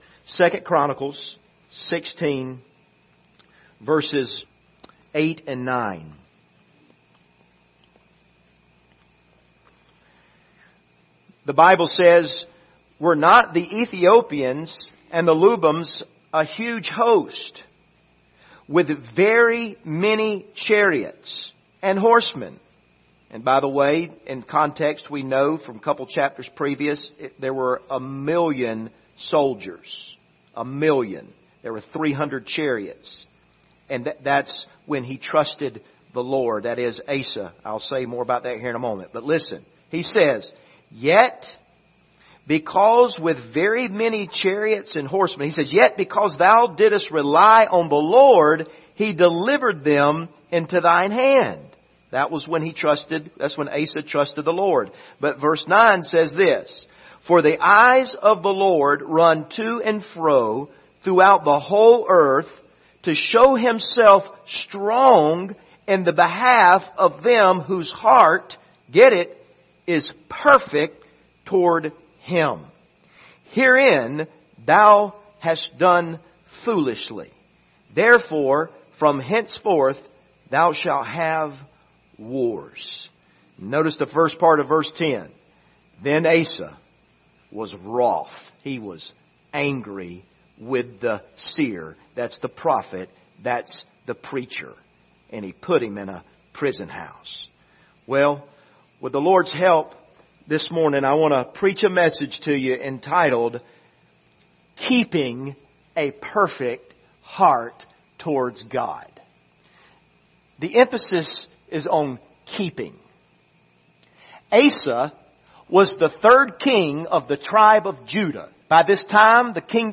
Service Type: Sunday Morning Topics: faith , idolatry , trust